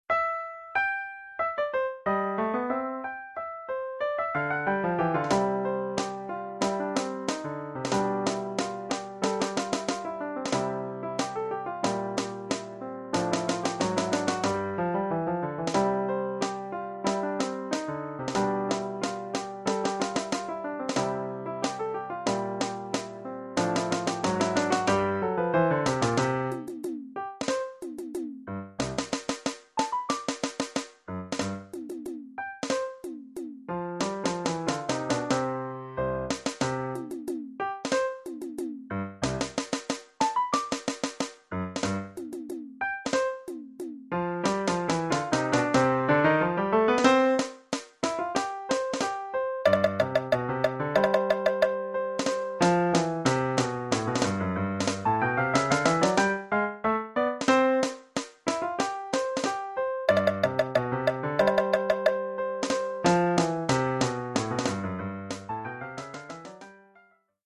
Oeuvre pour caisse claire et piano.